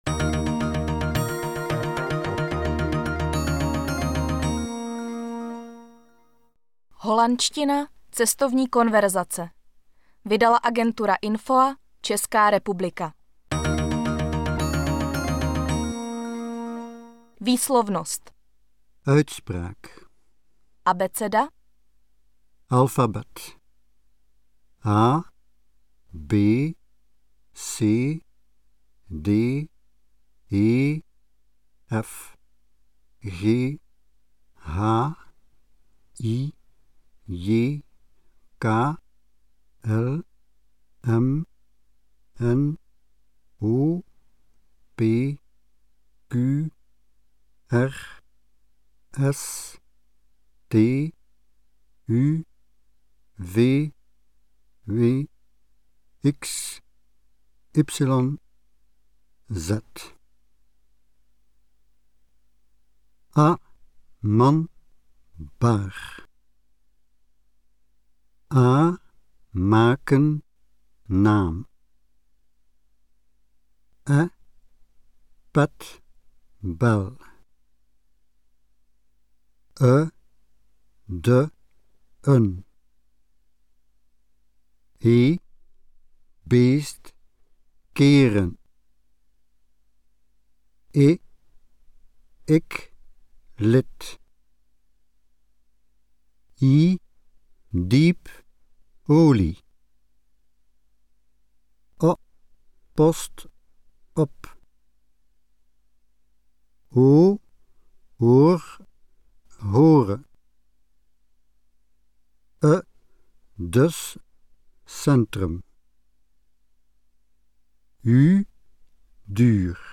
Obsahuje 32 témat k snadnému dorozumění, více než 500 konverzačních obratů s výslovností, samostudium formou poslechu a opakování.